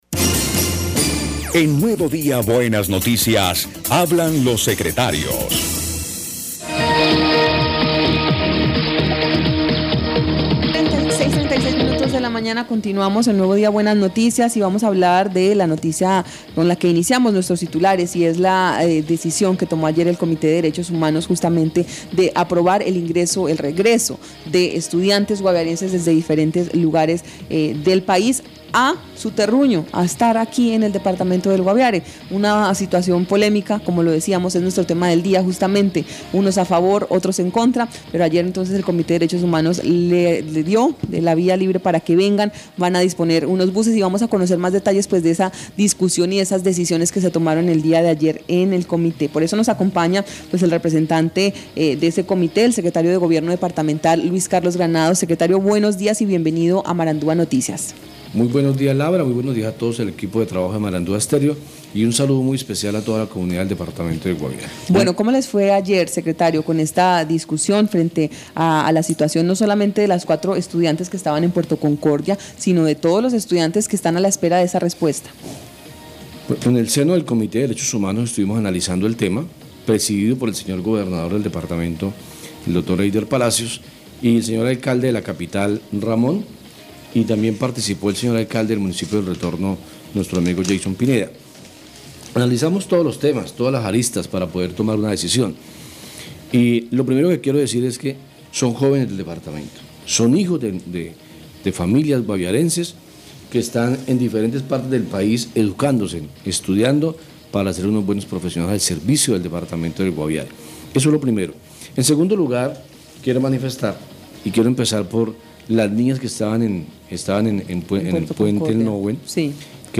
Escuche a Carlos Granado, secretario de Gobierno del Guaviare.